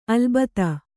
♪ alubdha